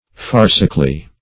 -- Far"ci*cal*ly, adv. -Far"ci*cal*ness, n.